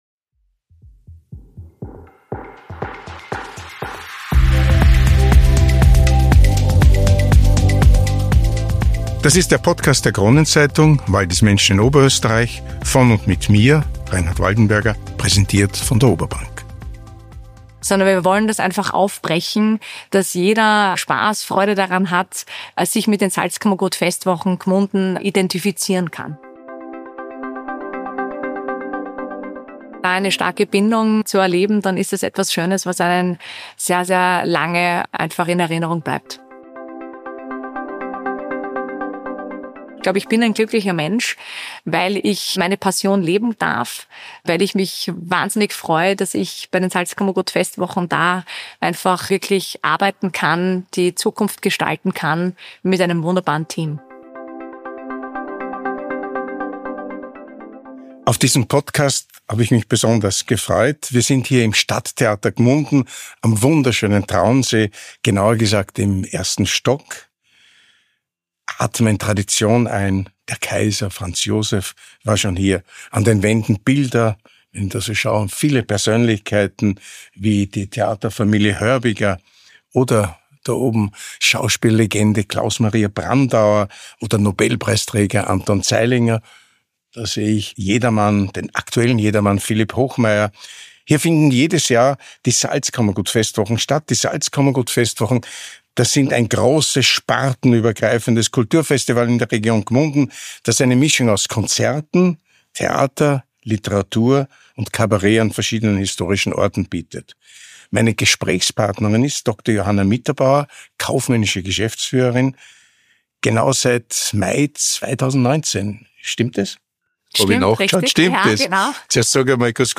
Ein inspirierendes, nahbares Gespräch mit einer Frau, die Kultur als Lebenselixier versteht.